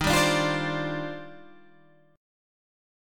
D#M13 chord